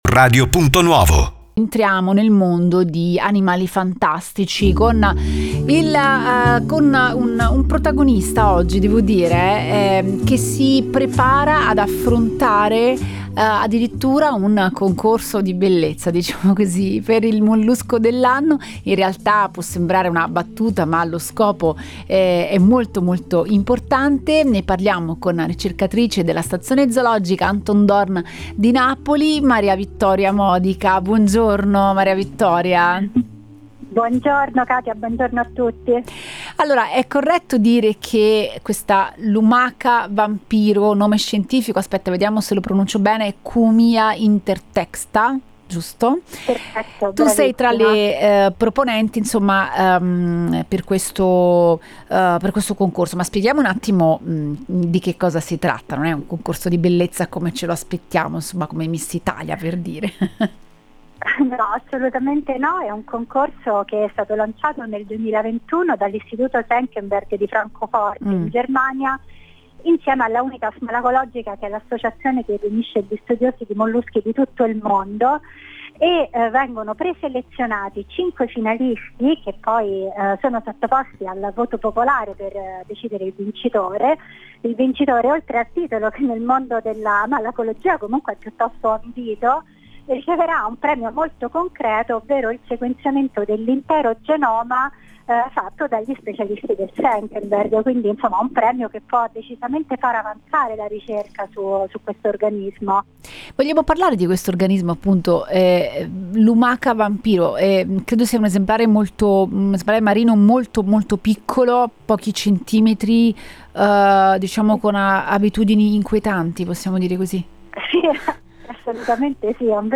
Ne abbiamo parlato ad Animali Fantastici con la ricercatrice